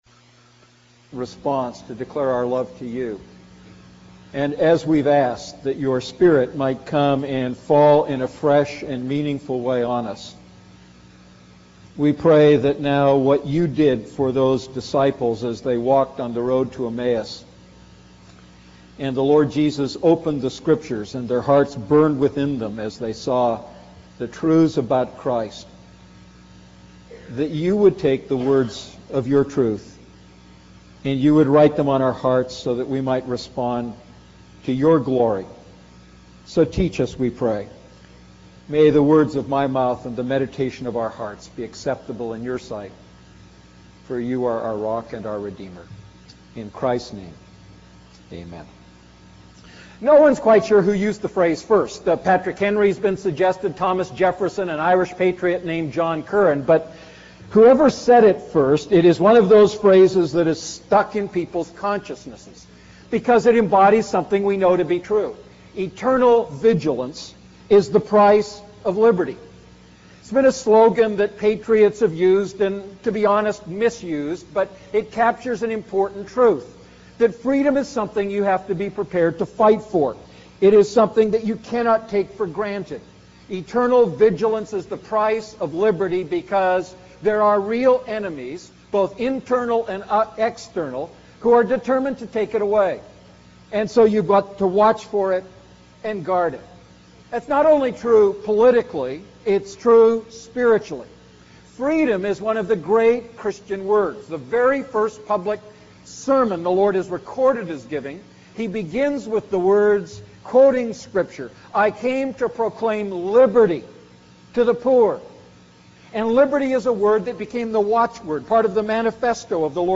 A message from the series "Holy Spirit."